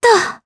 Laudia-Vox_Landing_jp.wav